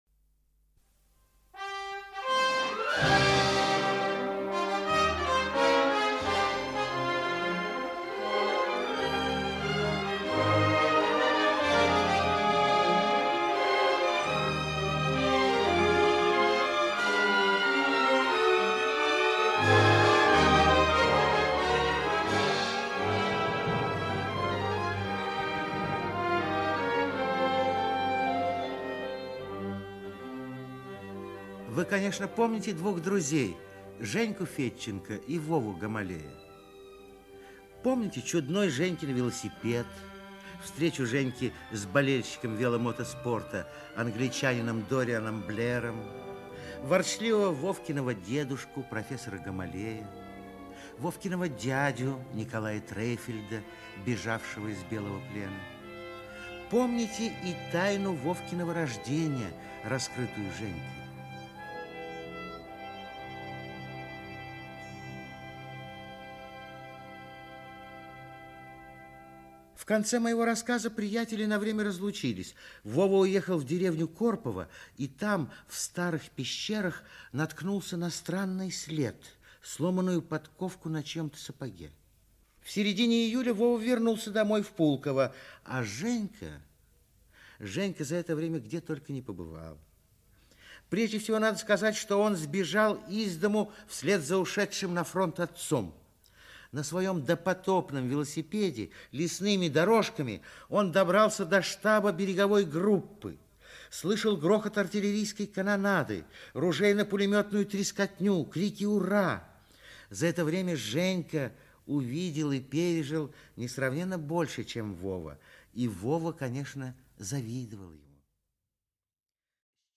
«Таинственный след» Автор Лев Успенский Читает аудиокнигу Актерский коллектив.